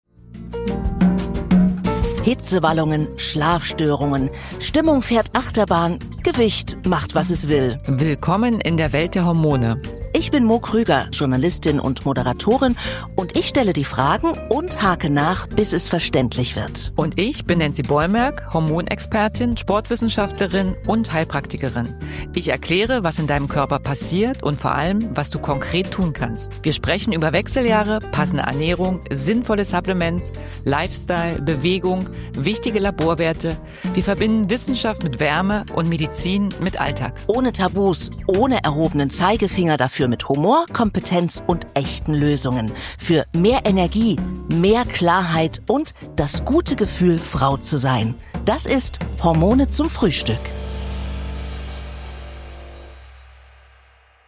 000 – Trailer: Worum geht es bei "Hormone zum Frühstück"?
sind Gespräche wie am Küchentisch: offen, vertraut und persönlich.